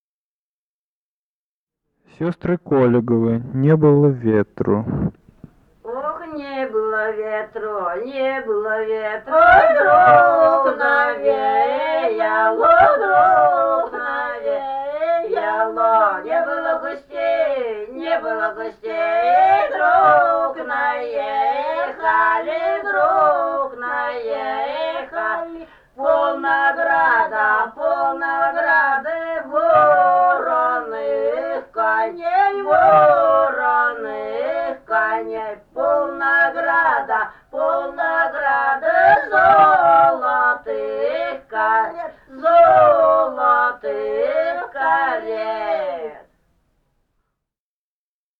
Этномузыкологические исследования и полевые материалы
«Ох, не было ветру» (свадебная).
Пермский край, д. Подвигаловка Кунгурского района, 1968 г.